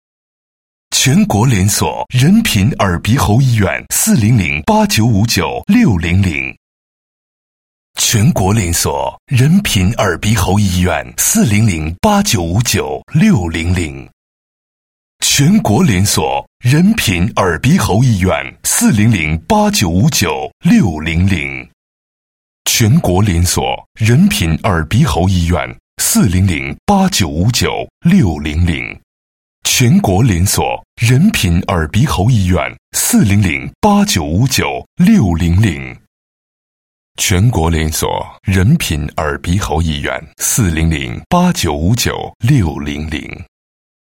女粤21_多媒体_广播提示_中粤英三语香港港口入闸